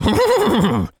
pgs/Assets/Audio/Animal_Impersonations/horse_neigh_calm_03.wav at master
horse_neigh_calm_03.wav